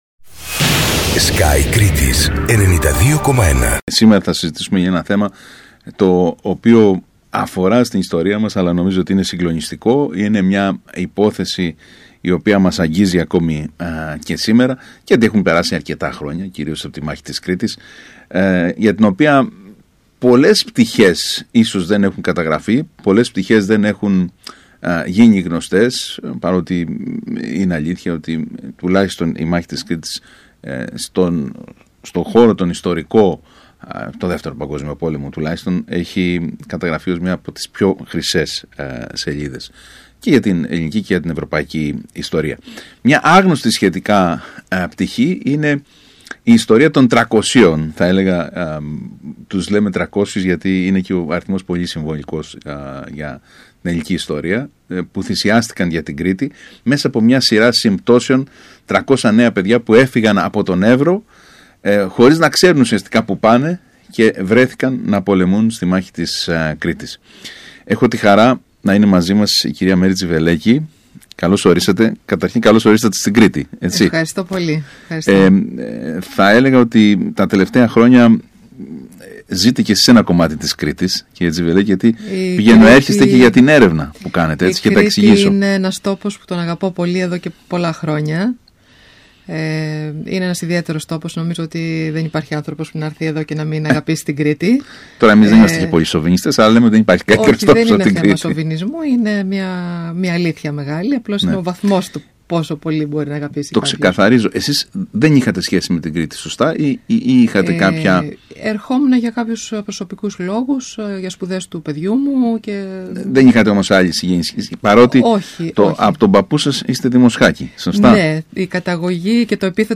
μιλώντας στον ΣΚΑΪ Κρήτης